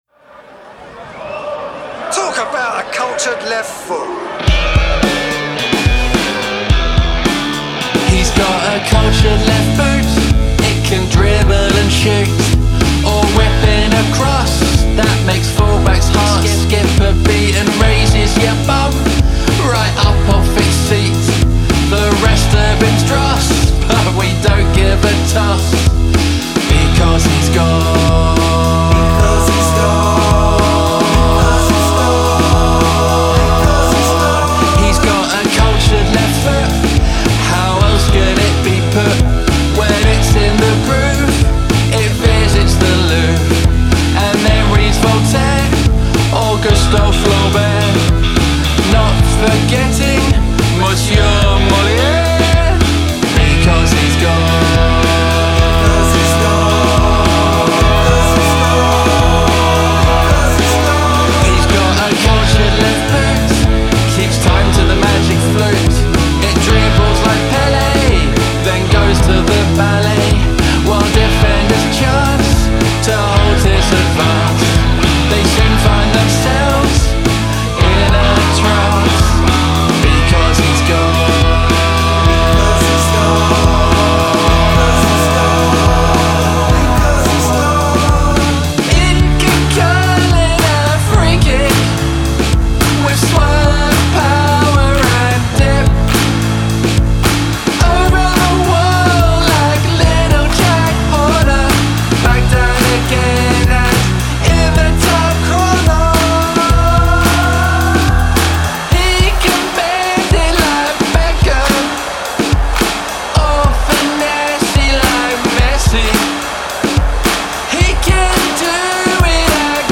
inspired by the sounds of the terraces.
Guitar, bass, drums